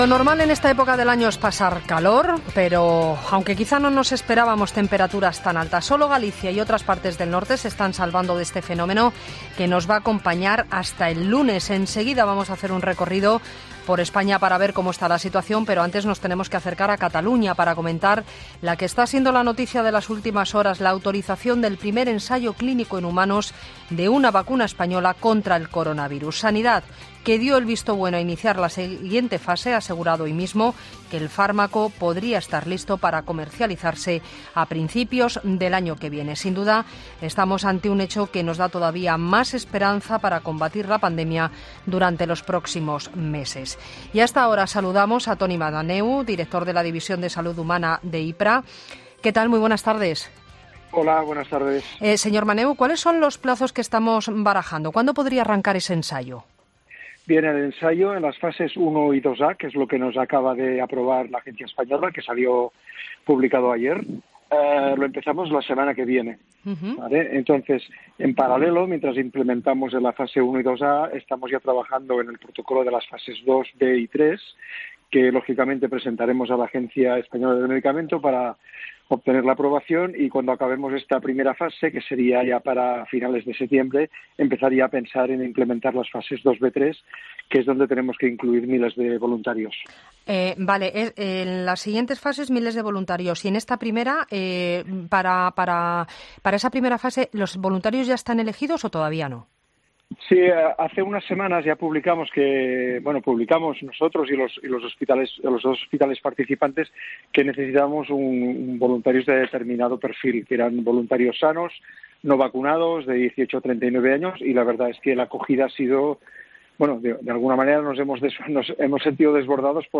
Otra de las cuestiones tratadas en los micrófonos de 'La Linterna' ha sido si los voluntarios ya han sido elegidos o por el momento a lo que el entrevistado afirmaba que, "hace unas semanas ya publicamos que necesitamos voluntarios de un determinado perfil. Nos hemos sentido desbordados por el alud de peticiones para participar, lo cual es una muy buena noticia para todos".